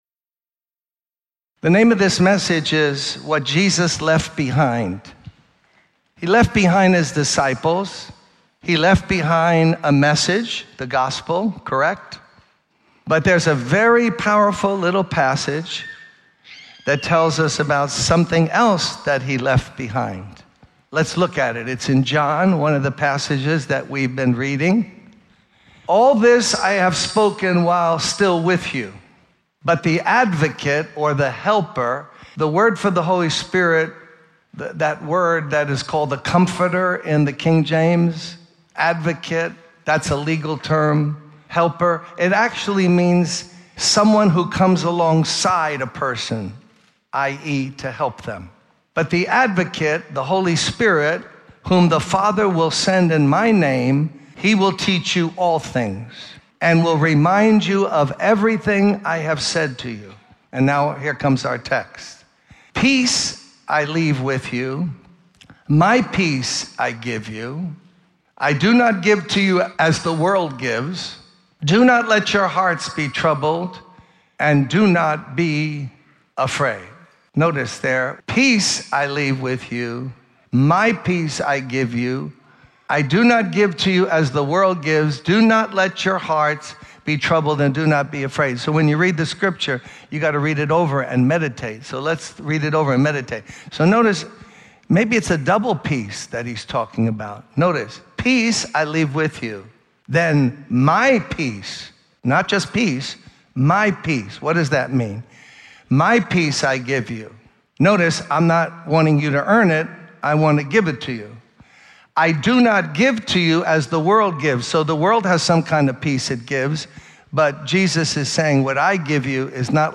In this sermon, Pastor Symbola shares his personal experience of lacking peace and the impact it had on his emotional and mental well-being.